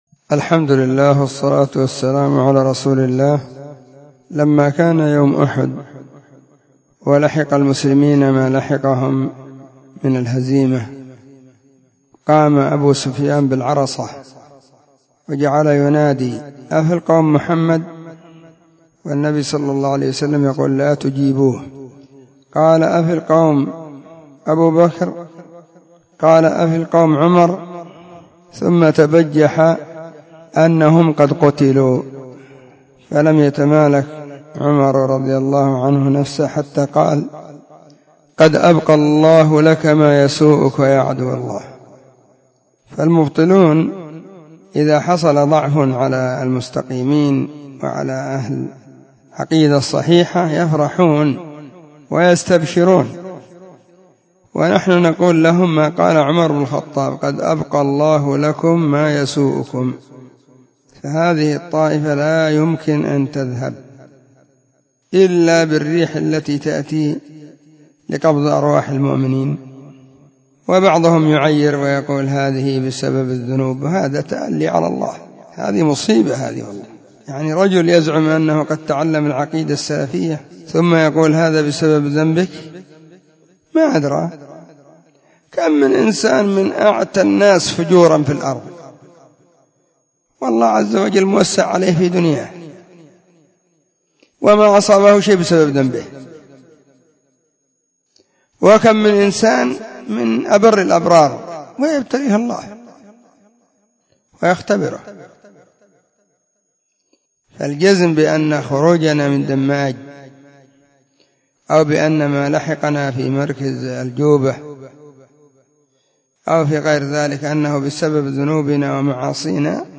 🎙 كلمة قيمة بعنوان: *💿قد أبقى الله لكم ما يسؤكم💿*
📢 مسجد – الصحابة – بالغيضة – المهرة، اليمن حرسها الله.